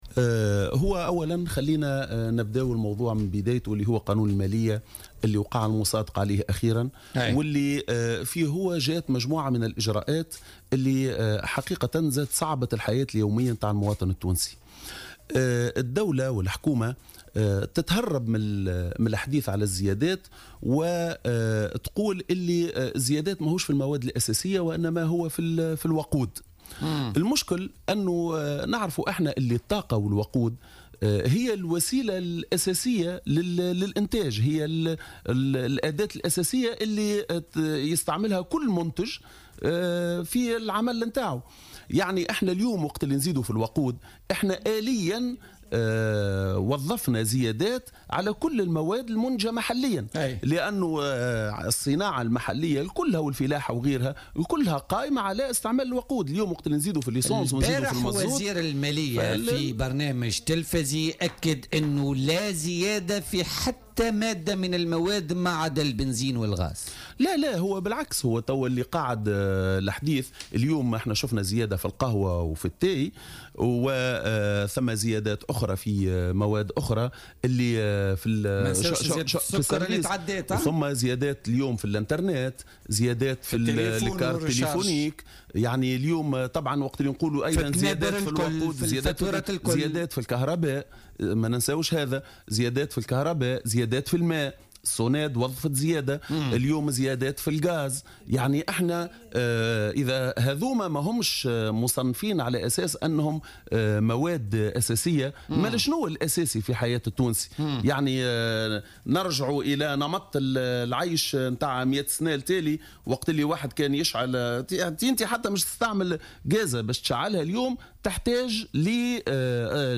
وأضاف الشعيبي ضيف "بوليتيكا" اليوم الأربعاء 03 جانفي 2018، أن البلاد لا يمكنها الخروج من أزمتها الحالية، دون سلم اجتماعية، التي أصبحت مهددة بسبب الزيادات المشطّة في الأسعار.